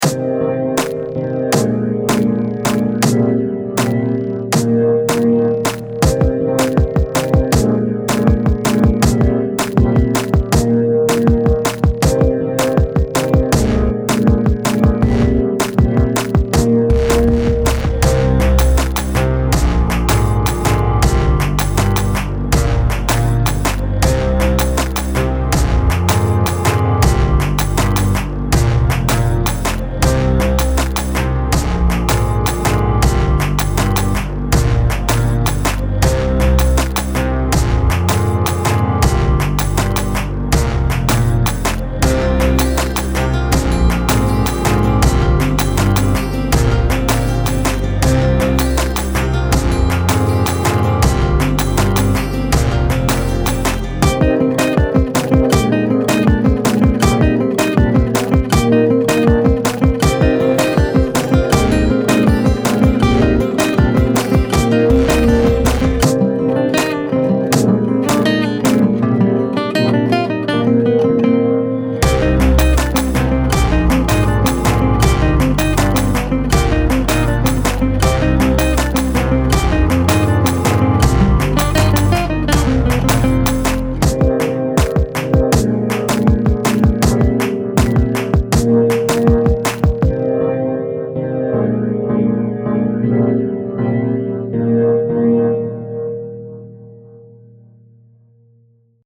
No instruments were used in the making of this song, it's purely computer action, the acoustic guitar parts toward the end are loops from logic.
Filed under: Instrumental Song | Comments (3)
I also like the drums specificaly were nicely more complex and the guitar sound went well.
The transition at 1:13 brings it all the parts home.